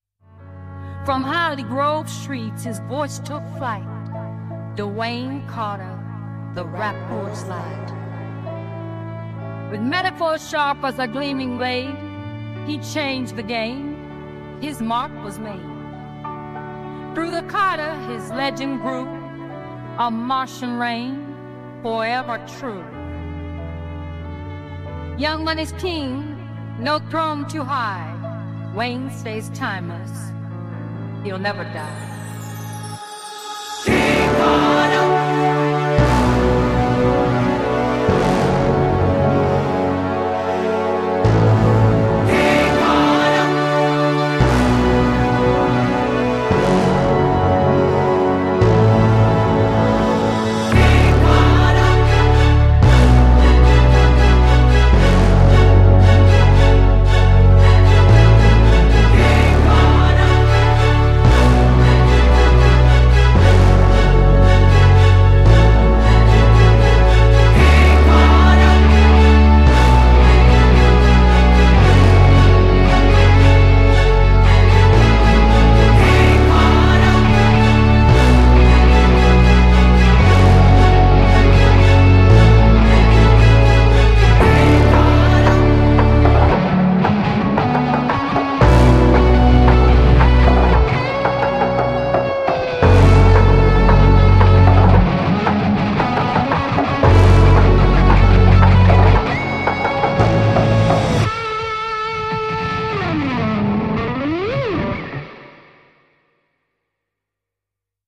smooth beats
His flow is still creative and full of energy.